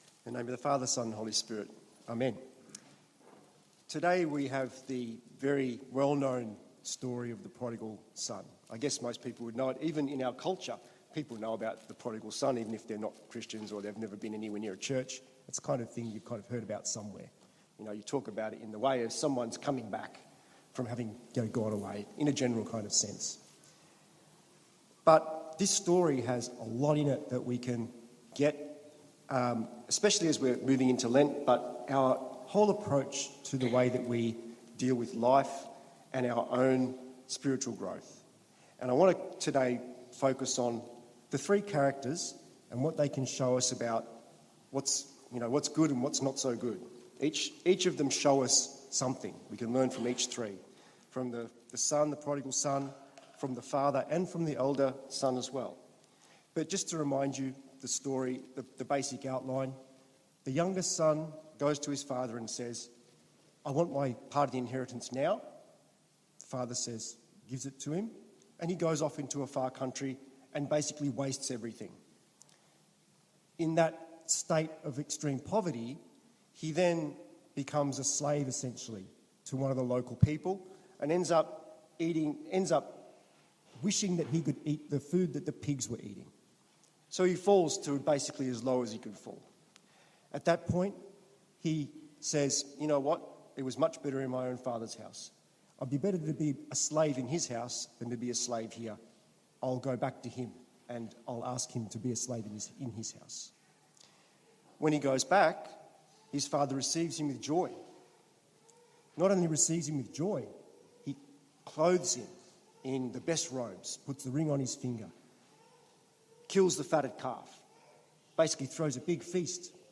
The Good Shepherd Orthodox Church: The Good Shepherd Orthodox Church: Sermons